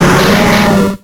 Cri de Smogo dans Pokémon X et Y.